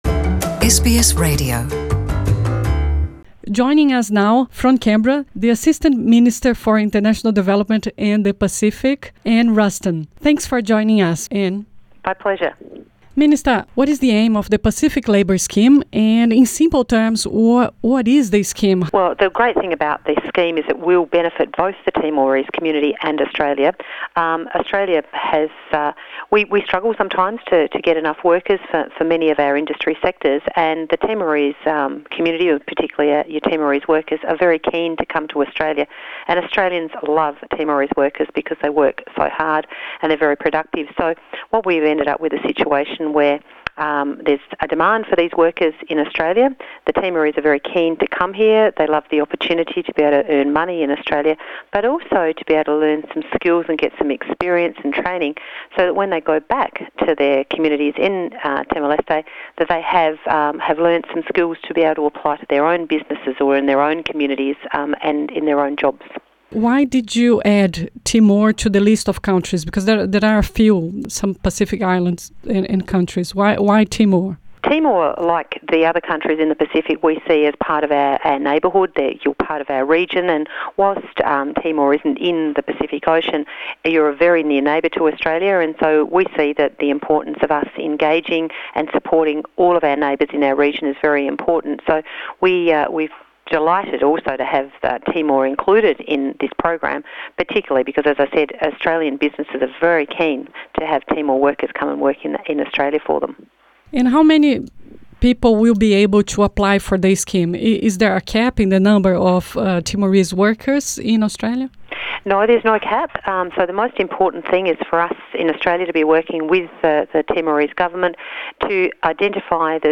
A Austrália está oferecendo aos timorenses a chance de trabalhar no país por até três anos, qualquer época do ano. A ministra-assistente para o Desenvolvimento Internacional e o Pacífico, Anne Ruston, falou a SBS em Português sobre o novo Pacific Labour Scheme. A entrevista foi feita em inglês.